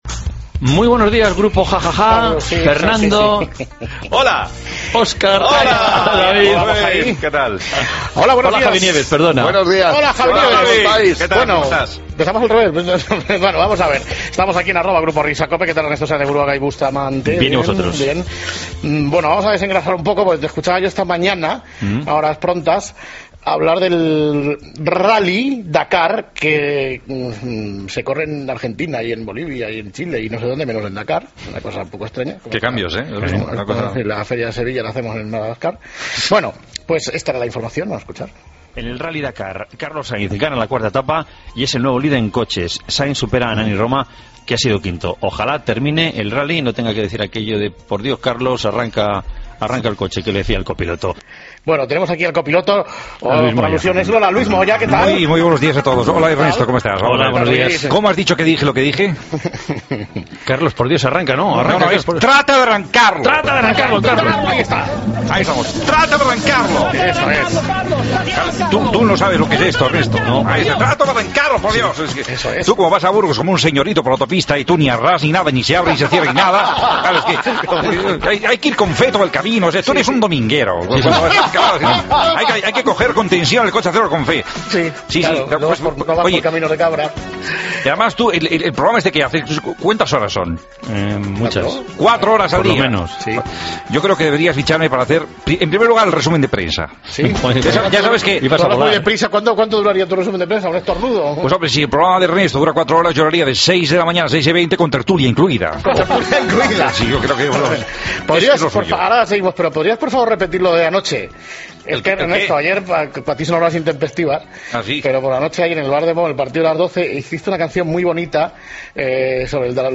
Luis Moya canta al Dakar